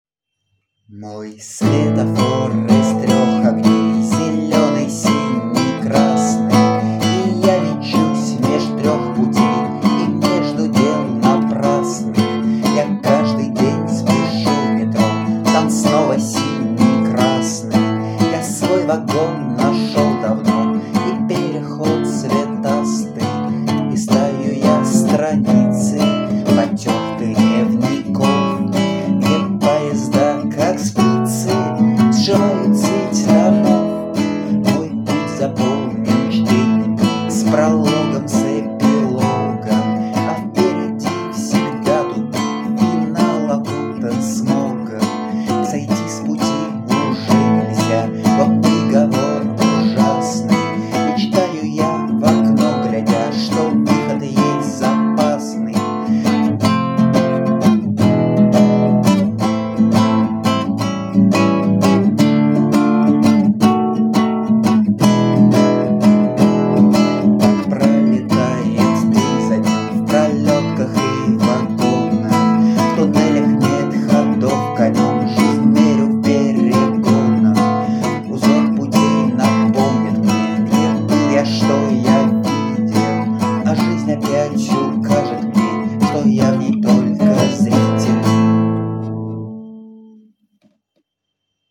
Ну и давайте по такому случаю запишу свою песенку 2010-го года.
В целом мне свои песни кажутся довольно кринжовыми (аж по трём параметрам: текст, музыка, исполнение), но это ж не повод не записывать их.